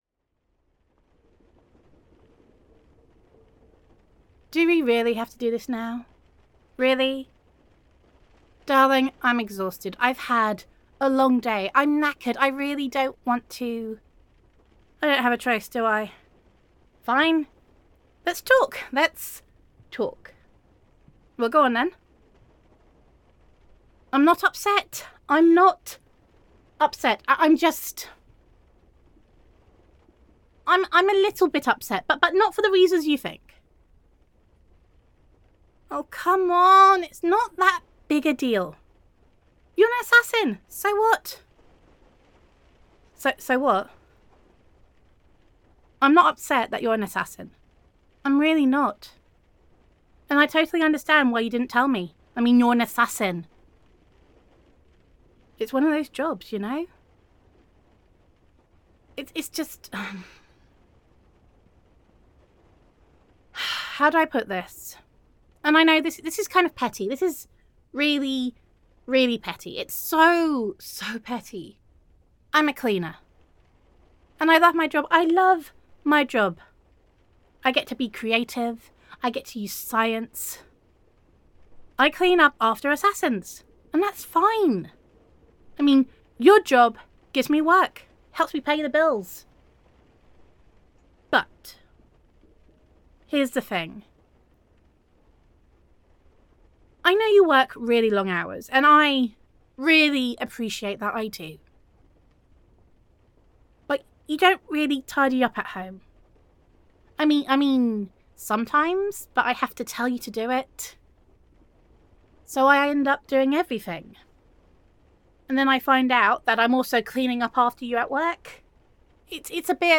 [F4A] Communication [Cleaner Roleplay][Girlfriend Roleplay][Love Confession][Assassin Listener][House Work][Gender Neutral][You Tell Your Girlfriend You Are an Assassin and She Is Miffed, but Not for the Reasons You Think]